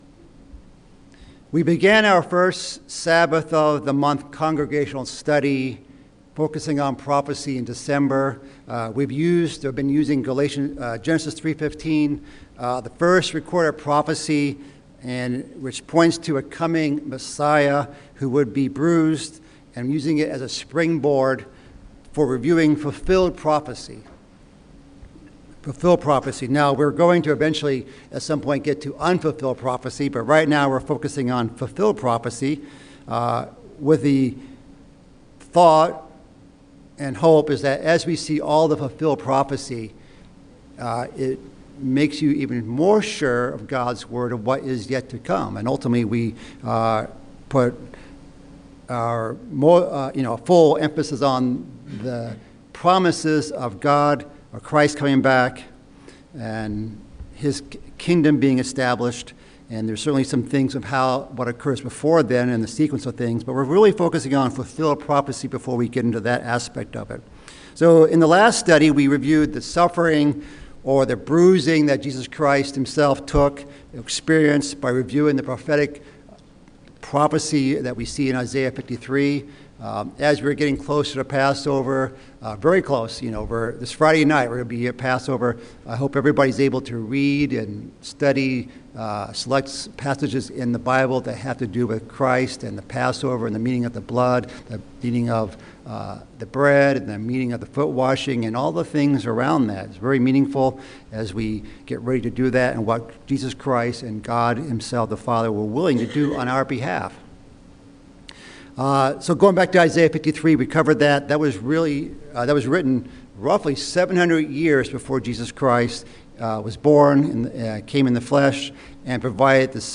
This study covers just a few prophecies that Jesus fulfilled to prove he is the Christ. This study, focusing towards the end of the study on Psalm 22, is also a good sermon in our Passover preparations.